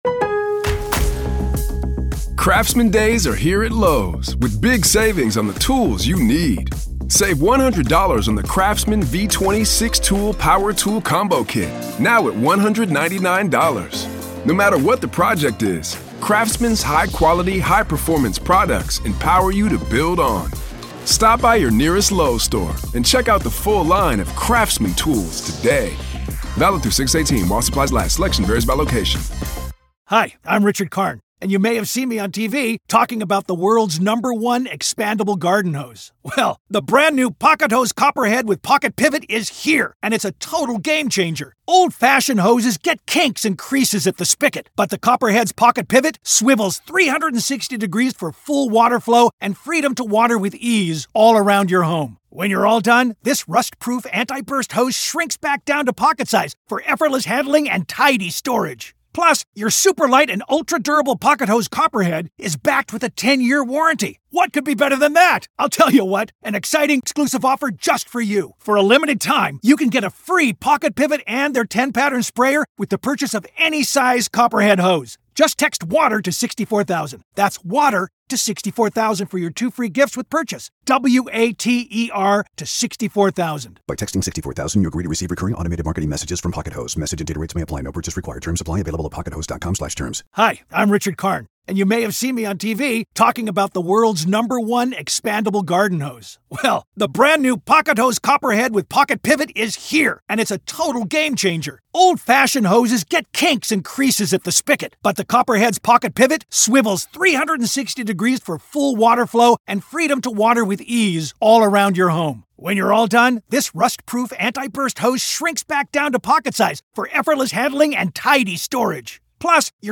This is audio from the courtroom in the high-profile murder conspiracy trial of Lori Vallow Daybell in Arizona.